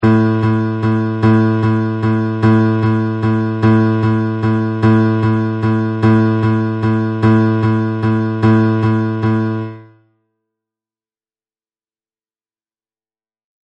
2.Temps ternaire :
Dans un temps ternaire, chaque temps fort est suivie de deux sous pulsations.
ternaire.mp3